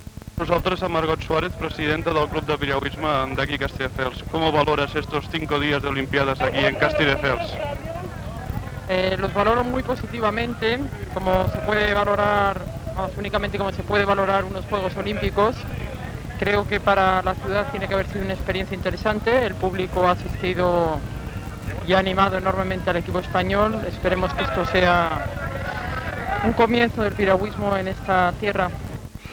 Declaracions
Informatiu